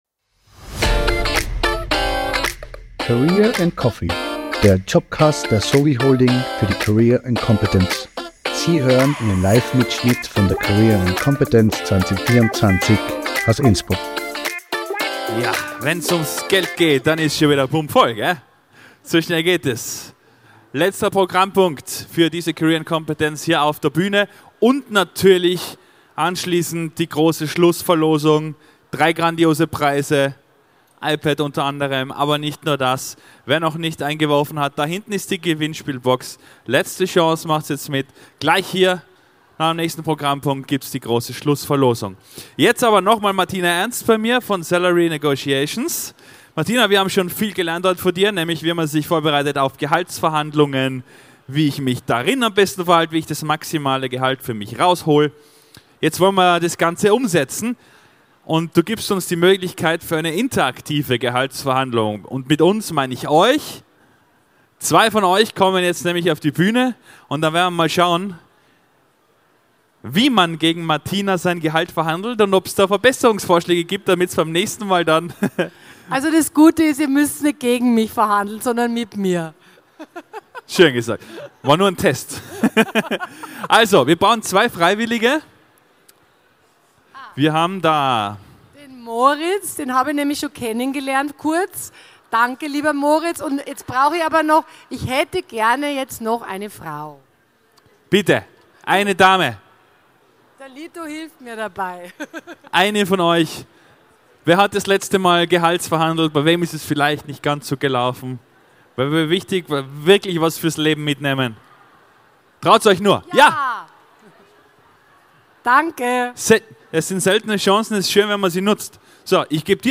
Livemitschnitt von der career & competence am 24. April 2024 im Congress Innsbruck.